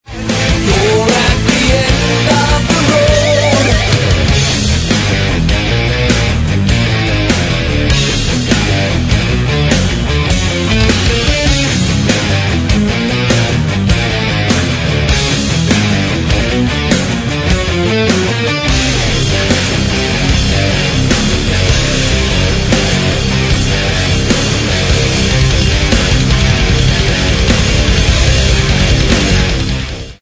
A very good first effort for this norwegian progmetal band.
mixing technical proficiency with melodic passages